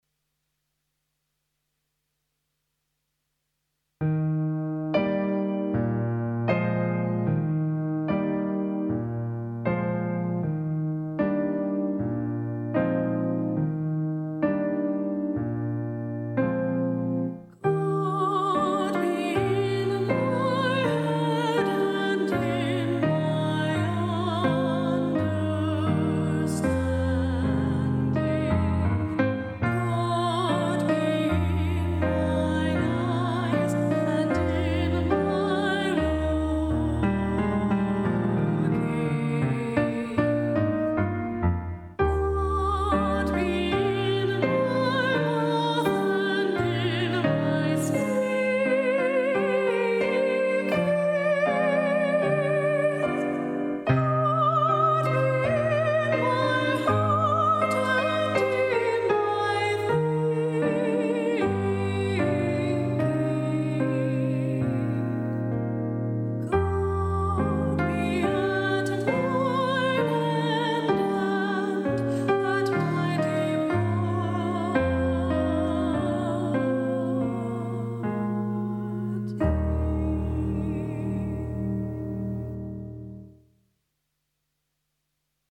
Solo / Unison voice with Keyboard accompaniment
This short anthem
voice with piano accompaniment.
The demonstration version is sung by